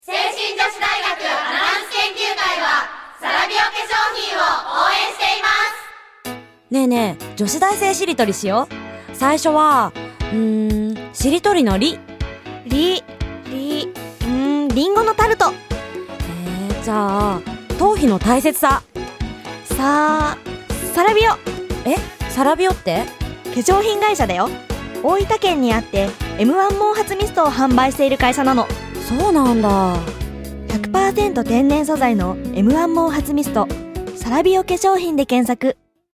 どれも清々しいお声で、皆さんの笑顔が浮かぶステキなＣＭです！
聖心女子大学アナウンス研究会による「ラジオCM」